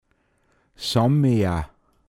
Pinzgauer Mundart Lexikon
Details zum Wort: Såmmea. Mundart Begriff für Sammler